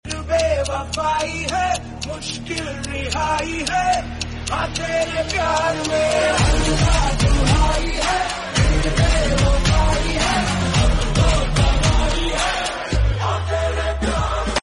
China Brand Sound Salencer 💯🔥Golden Sound Effects Free Download